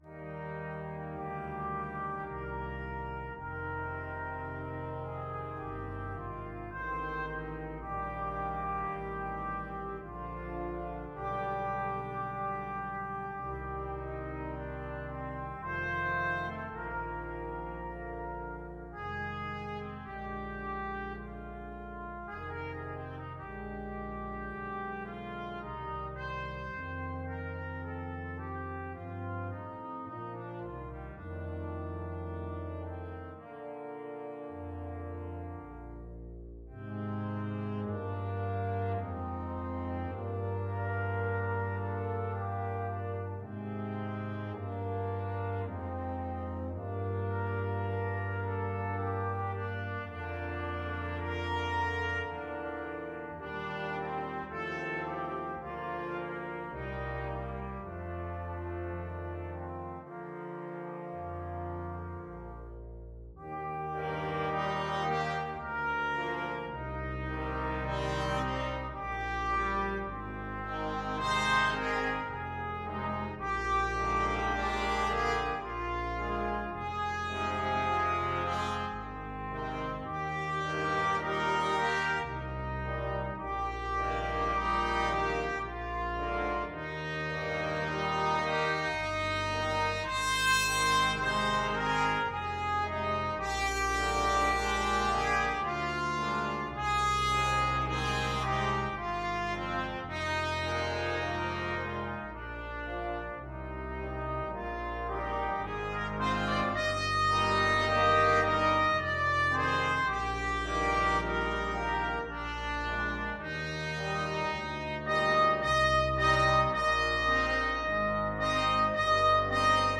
Free Sheet music for Brass Quintet
Trumpet 1Trumpet 2French HornTromboneTuba
3/4 (View more 3/4 Music)
Andante sostenuto ( = 54)
Brass Quintet  (View more Intermediate Brass Quintet Music)
Classical (View more Classical Brass Quintet Music)